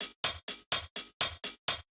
Index of /musicradar/ultimate-hihat-samples/125bpm
UHH_ElectroHatA_125-01.wav